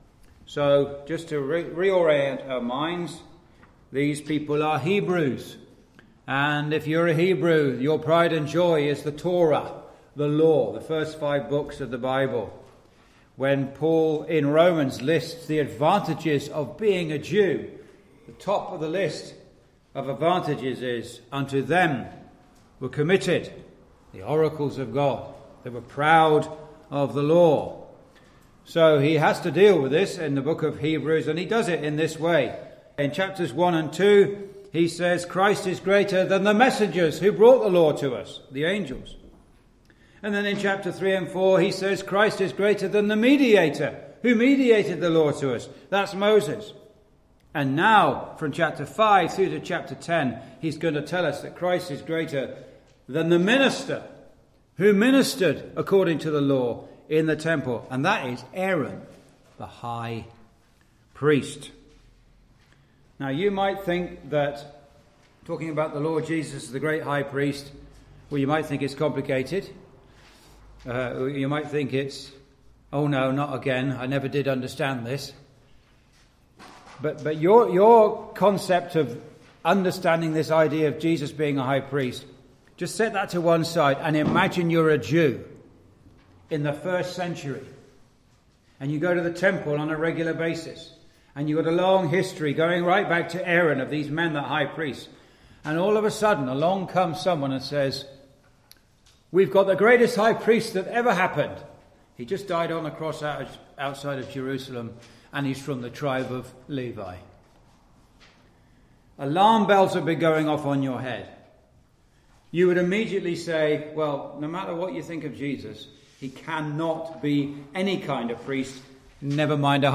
Verse by Verse Exposition